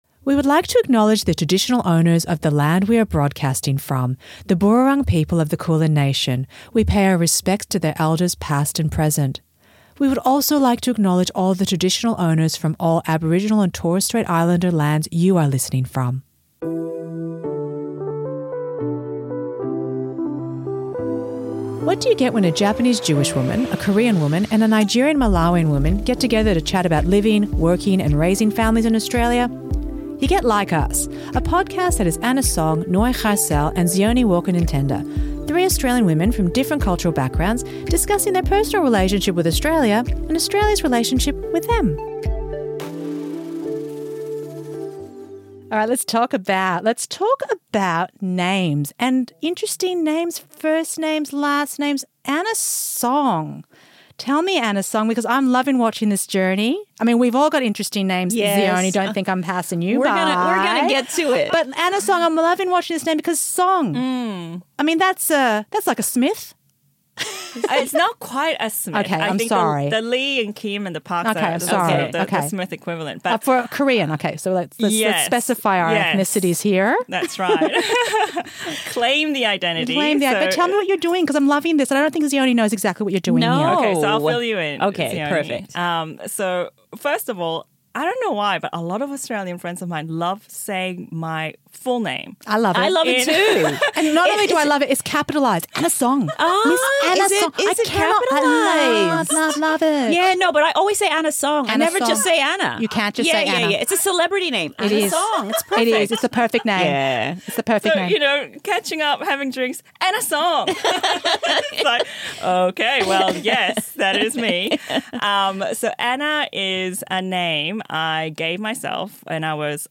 Introducing Like Us: Three Australian women from different cultural backgrounds discussing their personal relationship with Australia and Australia’s relationship with them.